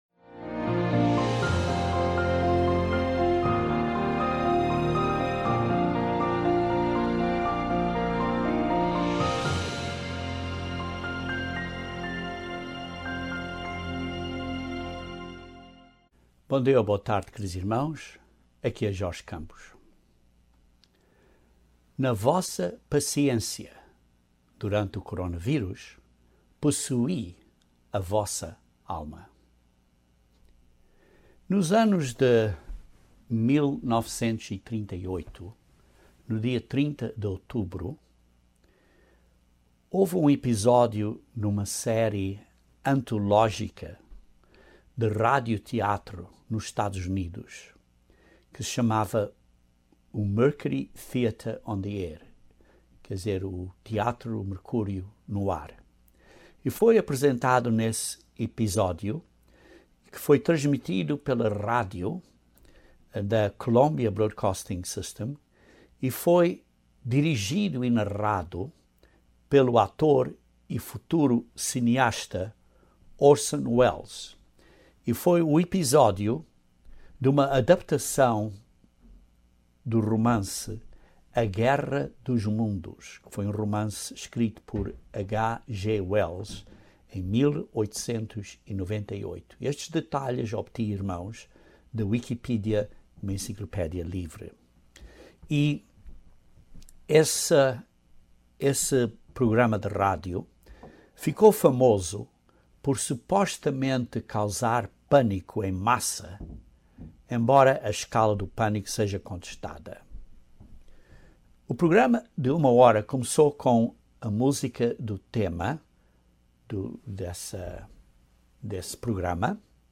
Precisamos de paciência e perseverança. Este sermão aborda o tema de perseverança e paciência, pois a mesma palavra grega "hupomone" é traduzida em algumas versões como paciência e em outras versões como perseverança.